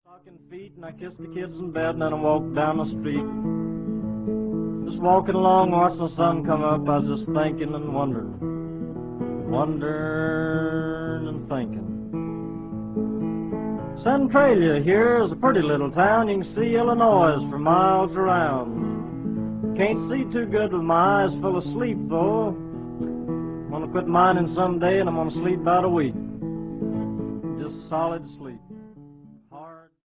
guitar
Recorded in New York between 1944 and 1949.